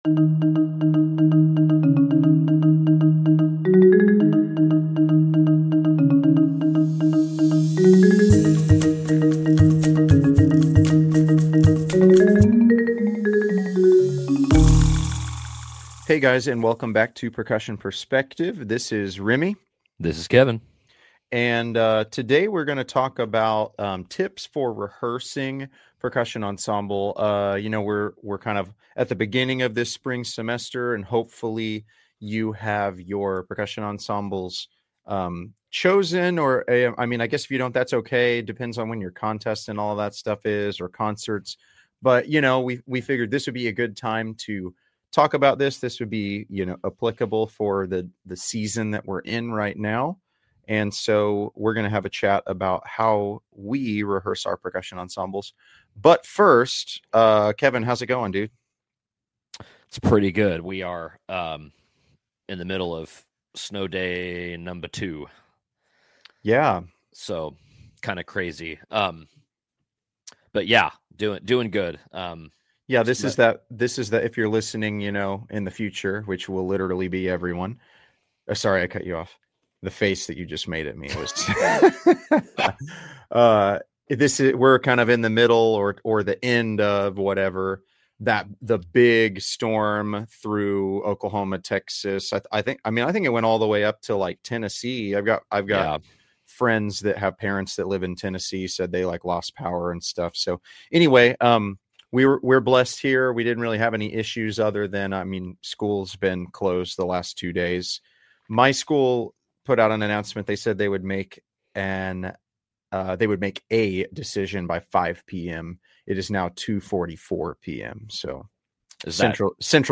Two best friends that are also percussion directors aiming to dive deeper into topics relating to a school band/percussion program. Our hope is to provide a resource for music educators to further understand the ins and outs of percussion.